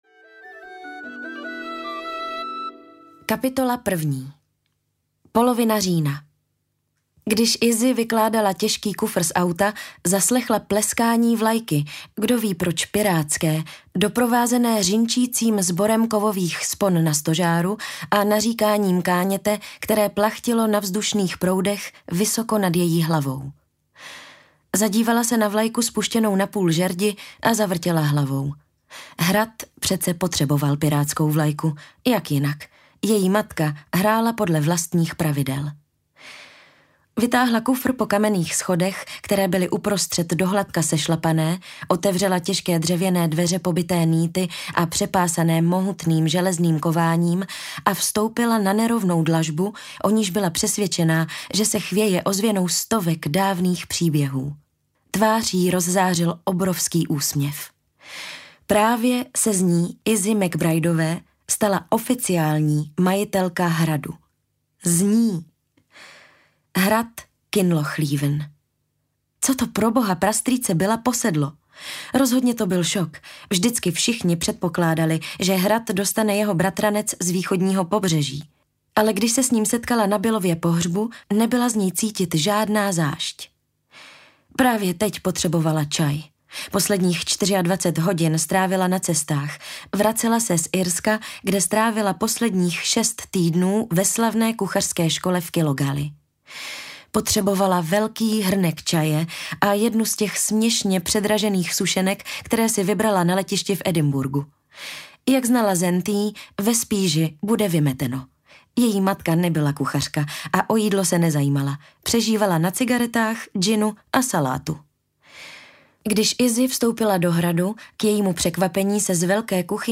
Hrad ve Skotsku audiokniha
Ukázka z knihy